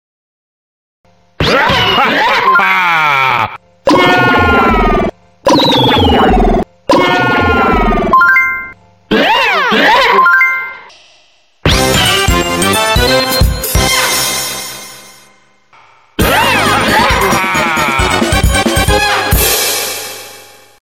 Yoshi sounds in 1999 are sound effects free download
Yoshi sounds in 1999 are different compared to now lol